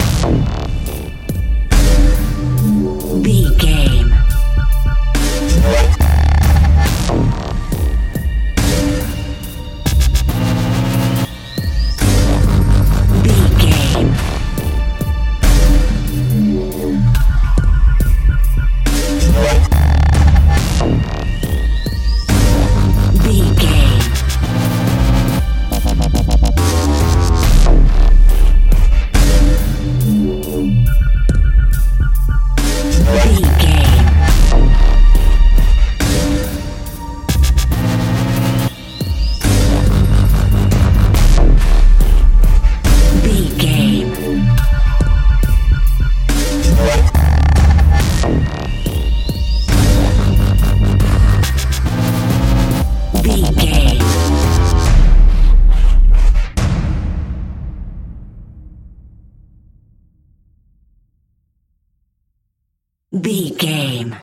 Aeolian/Minor
synthesiser
drum machine
orchestral
orchestral hybrid
dubstep
aggressive
energetic
intense
strings
drums
bass
synth effects
wobbles
driving drum beat
epic